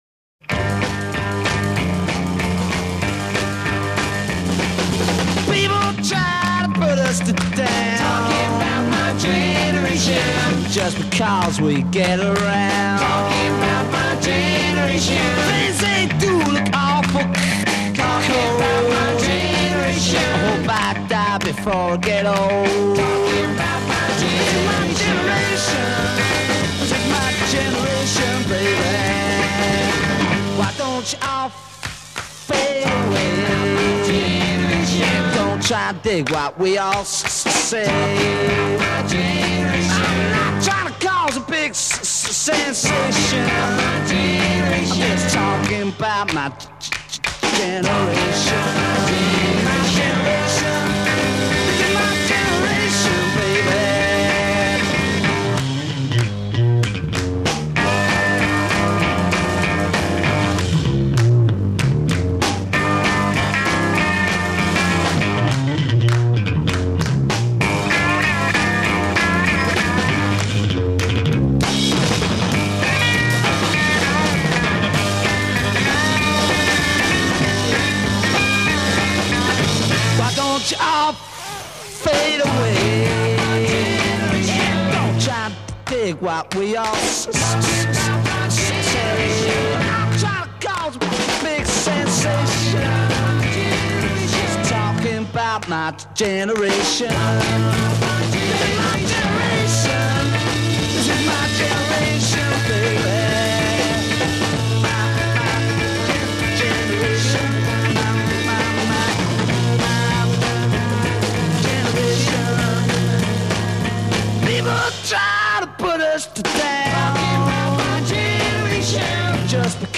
Recorded: IBC Studios, London, 13 October 1965
(G major)
Bass continues throughout. Hand claps punctuate.   a
Coda   17 Chorus repeats hook while lead vocal improvises. f